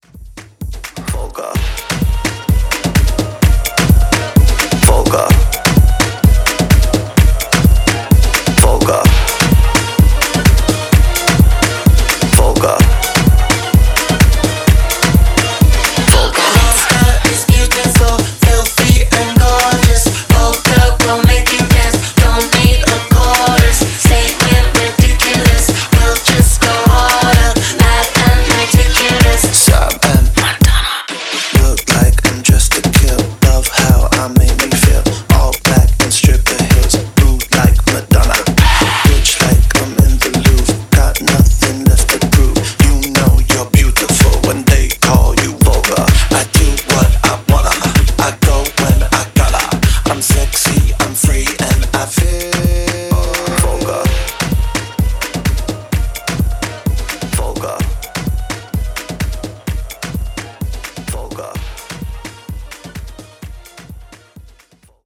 Genre: TOP40 Version: Clean BPM: 125 Time